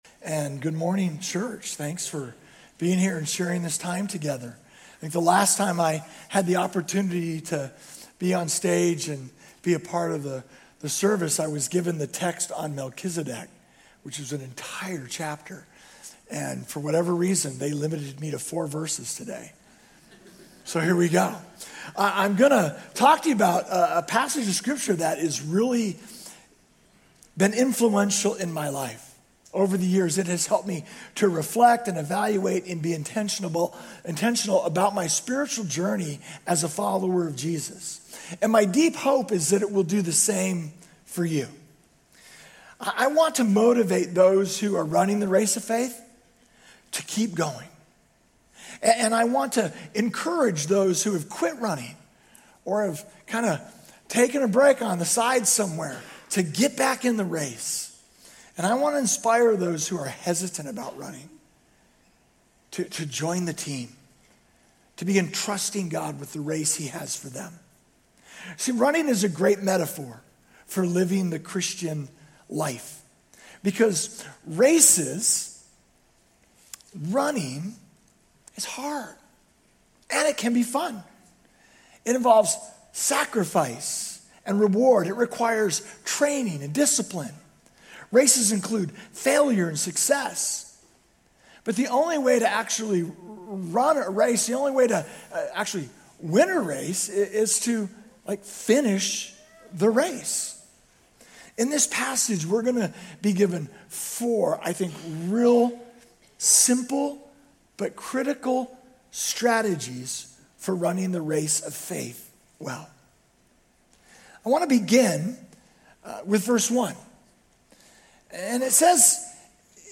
Grace Community Church University Blvd Campus Sermons 3_15 University Blvd Campus Mar 16 2026 | 00:30:23 1x 00:00 / 00:30:23 Subscribe Share RSS Feed Share Link Embed